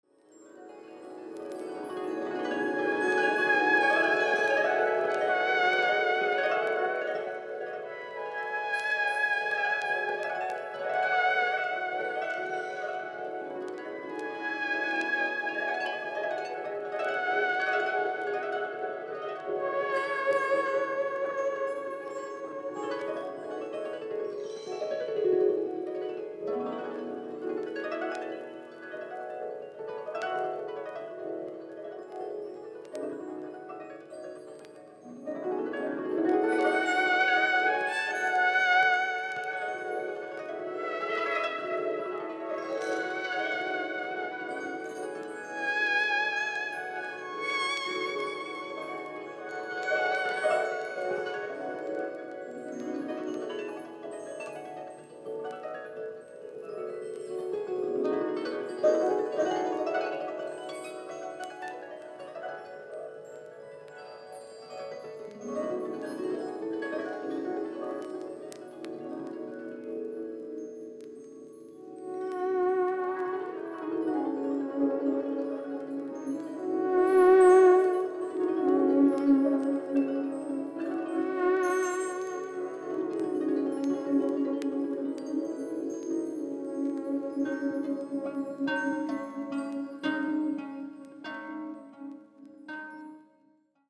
心地よく鳴り響きます。